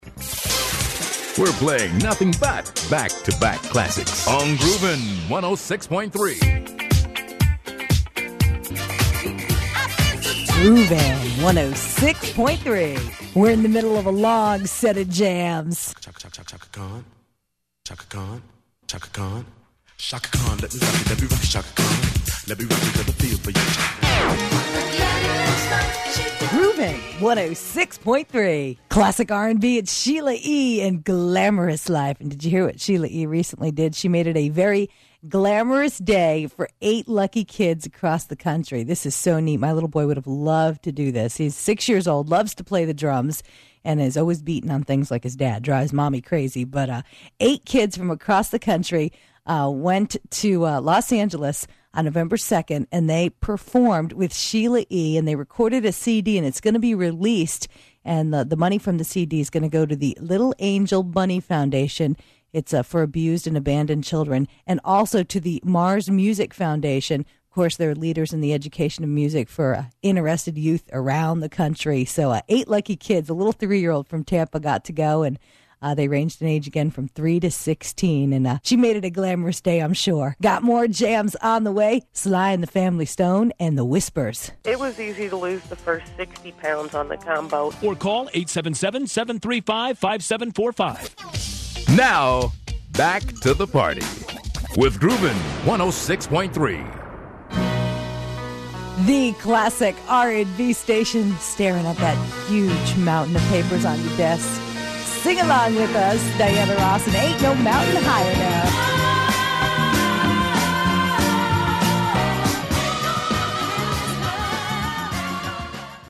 Network Demos
(Voice Tracked)
(Christian Hit Music)